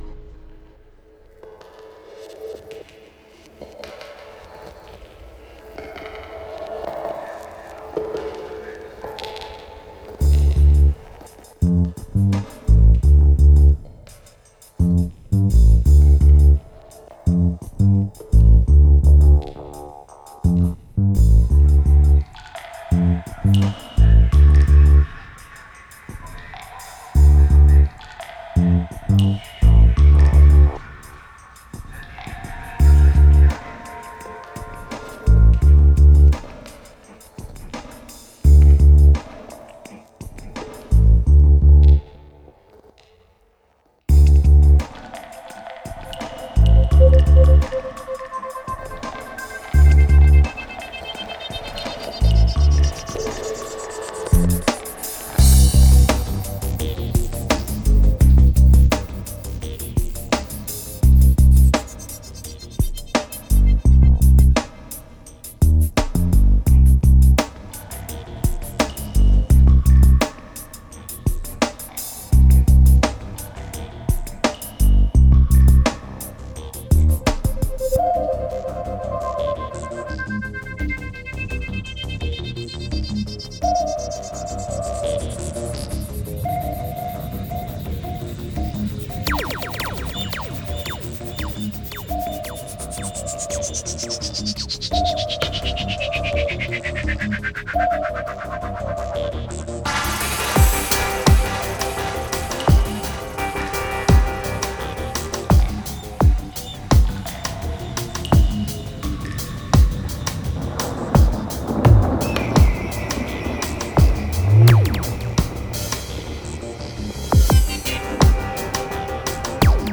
Genre: Psychill, Downtempo.